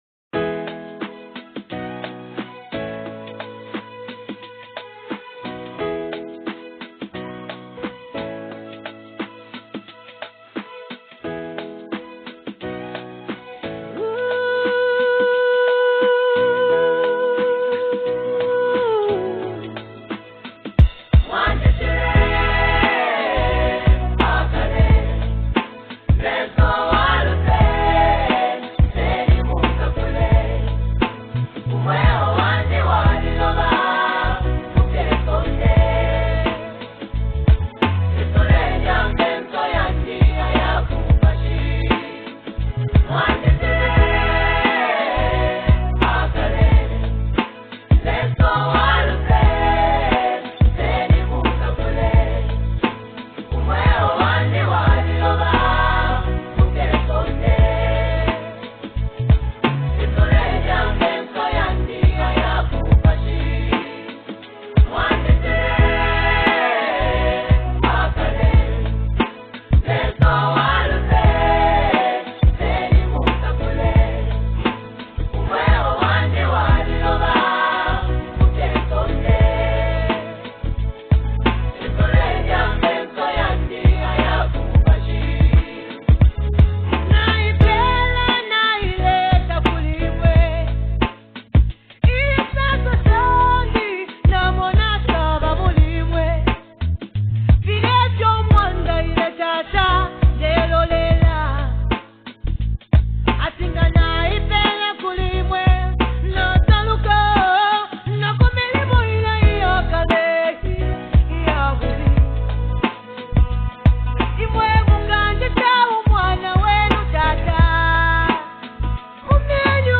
Gospel Music
With beautifully crafted lyrics and a soothing melody